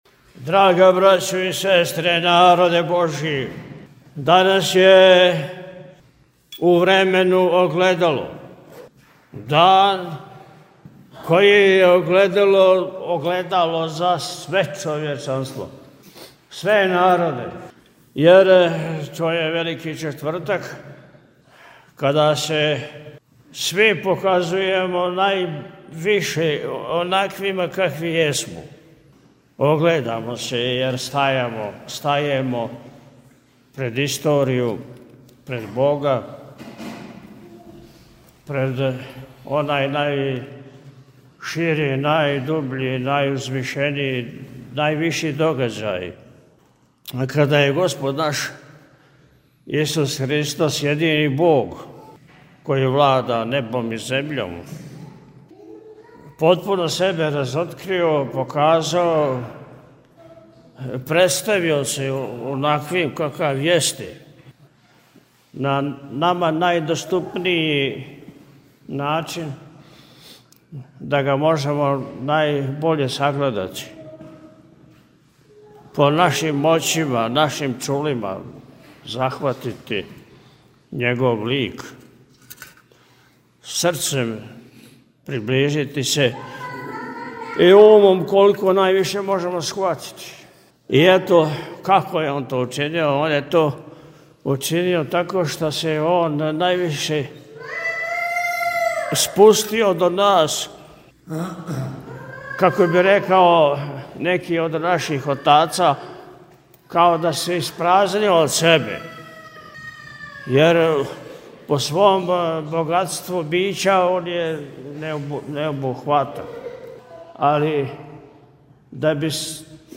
Митрополит Атанасије на Велики Четвртак служио у Сјеници - Eпархија Милешевска
Беседу Митрополита Атанасија можете послушати овде:
Sjenica-Veliki-Cetvrtak.mp3